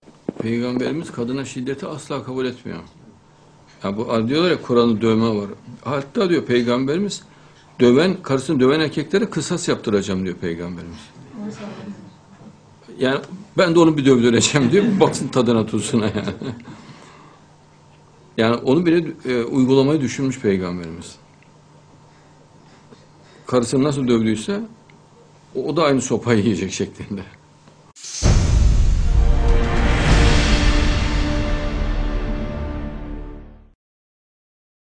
Excerpt from Mr. Adnan Oktar's Live Conversation on A9TV of December 11th, 2015 ADNAN OKTAR: Our Prophet (pbuh) never approved of violence against...